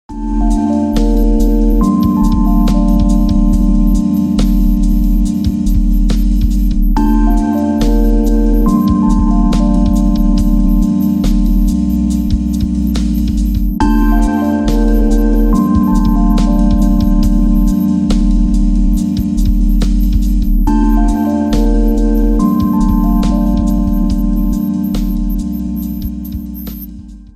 Alarme